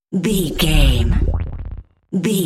Sound Effects
Atonal
magical
mystical